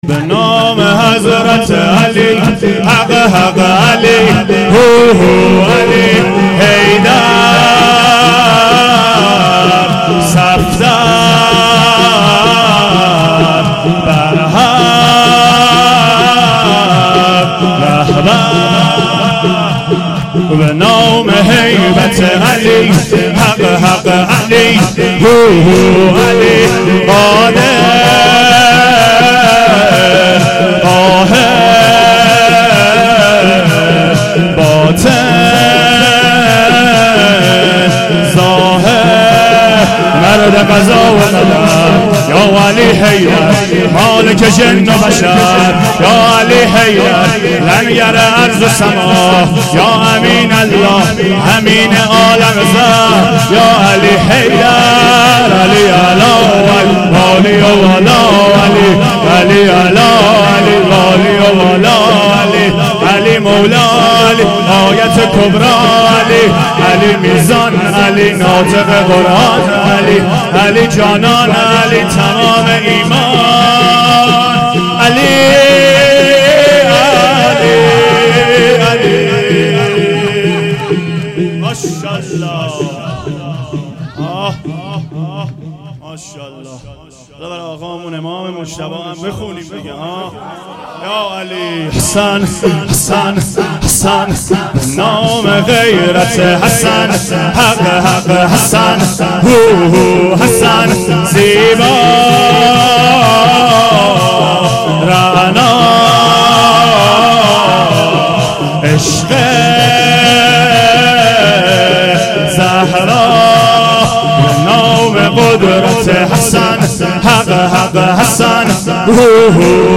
شور شب پنجم فاطمیه